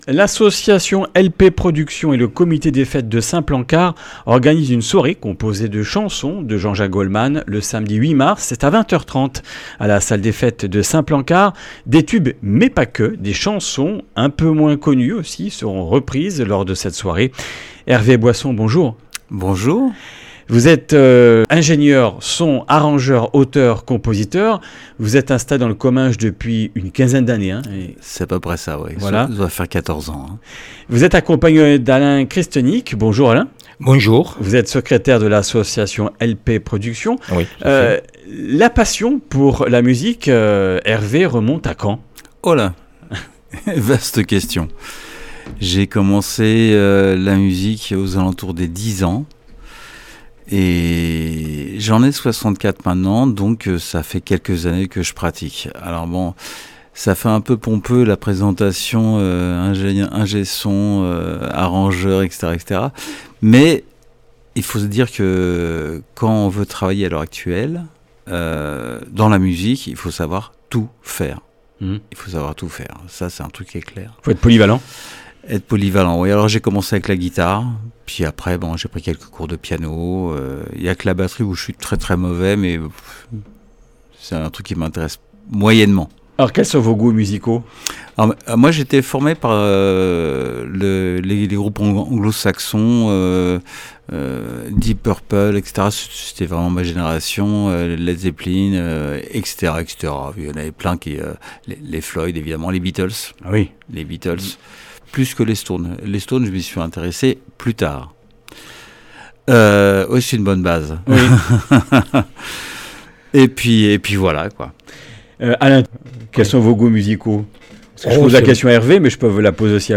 Comminges Interviews du 03 mars